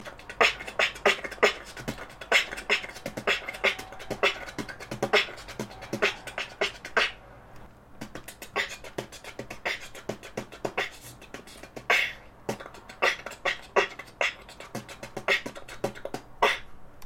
В начале я использую свой обычный клэп, а после небольшой остановки смещаю челюсть вперёд так, чтобы зубы были друг напротив друга.
clap.mp3